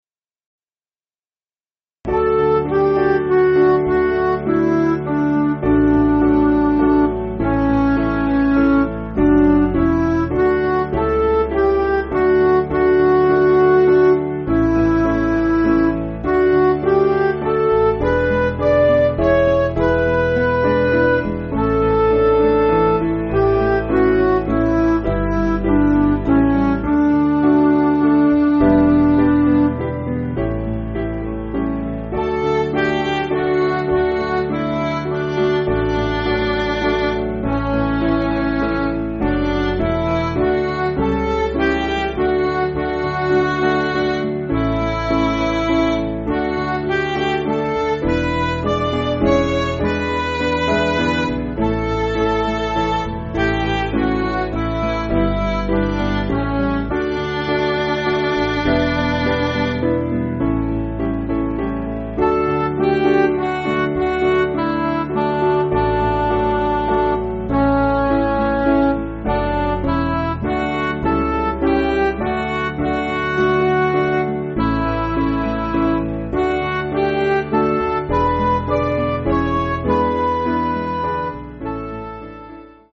Piano & Instrumental